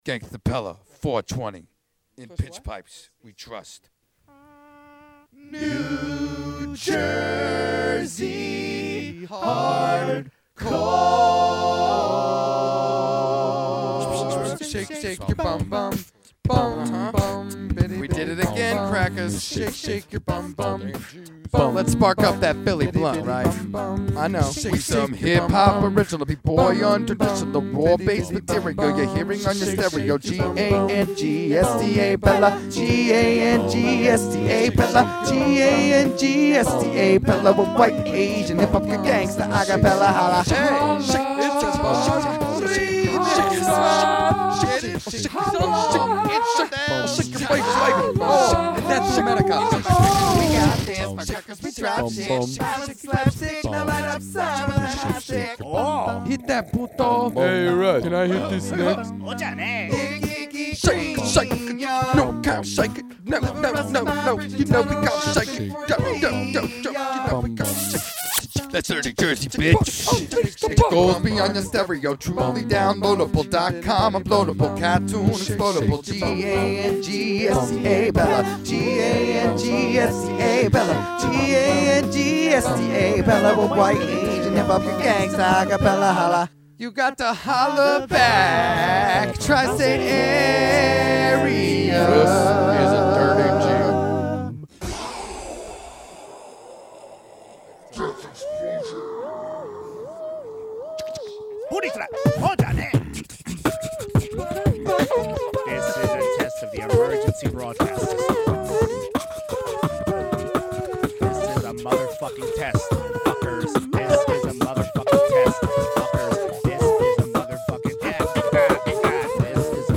Ruff' Studio Cut